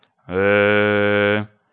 Vocale în format .wav - Vorbitorul #29